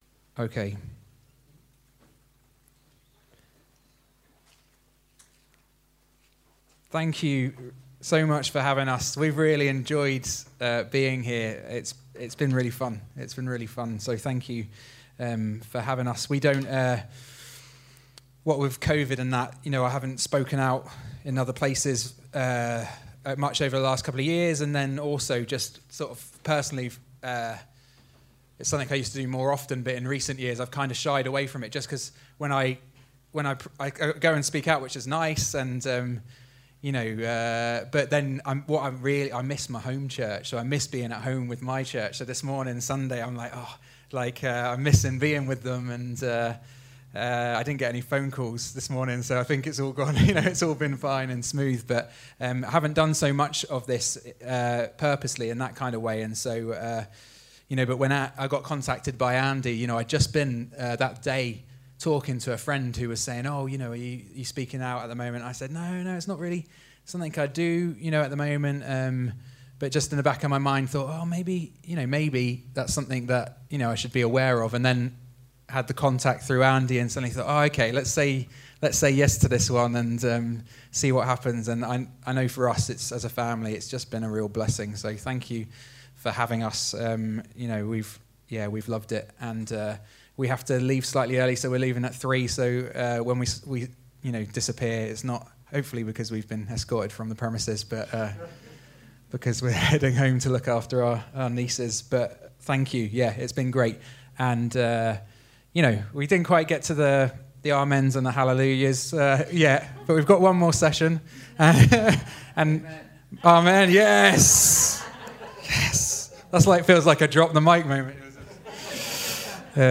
Home › Sermons › Session 4: Houseparty